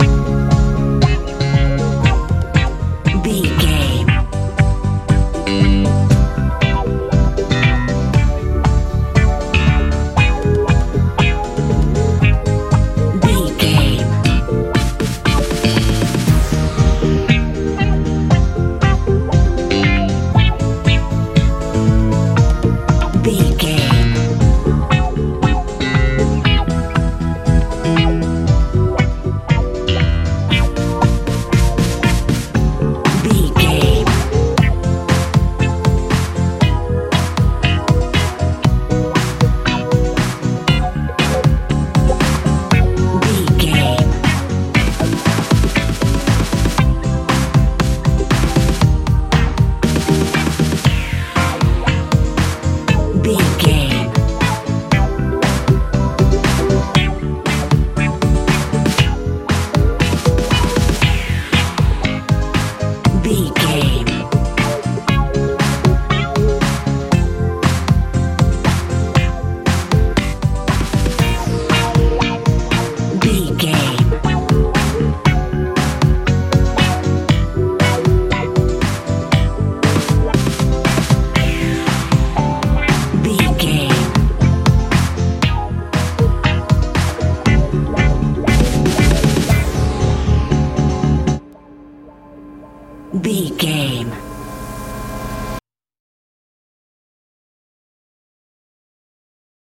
funk dance feel
Ionian/Major
C♯
groovy
funky
synthesiser
electric guitar
bass guitar
drums
70s
80s